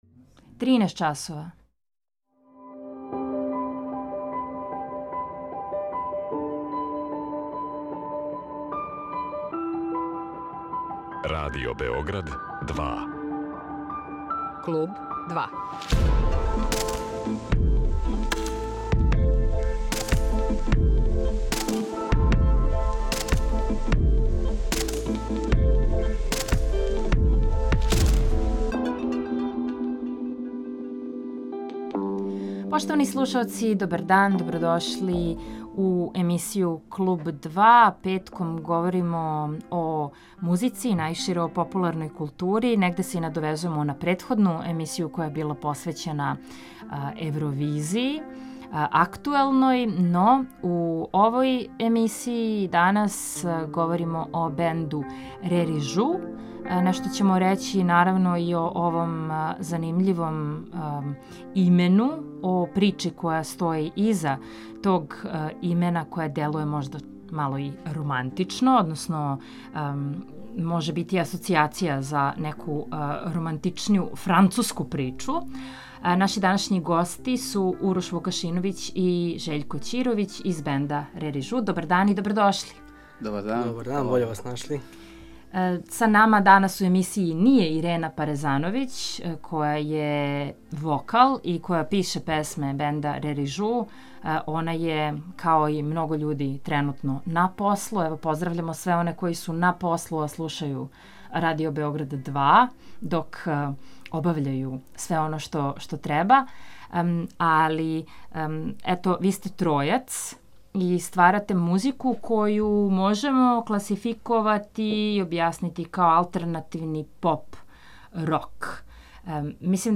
Овај бенд бави се алтернативином поп/рок музиком, са елементима експерименталног.